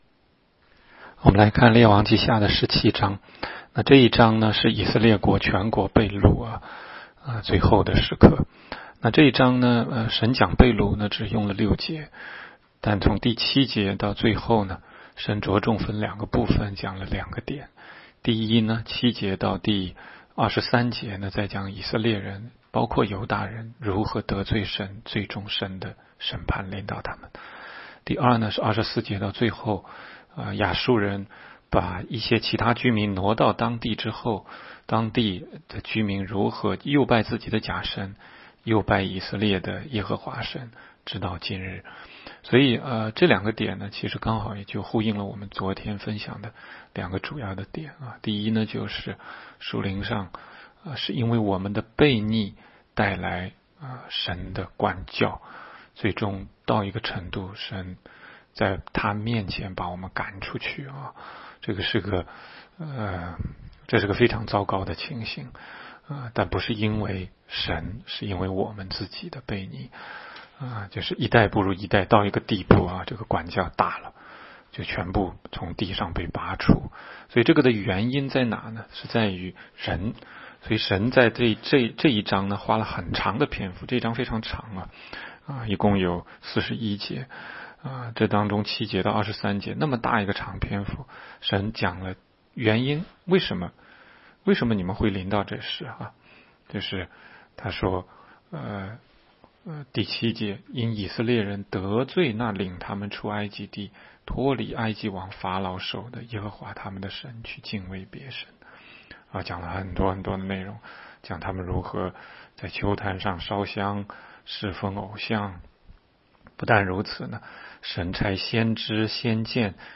16街讲道录音 - 每日读经-《列王纪下》17章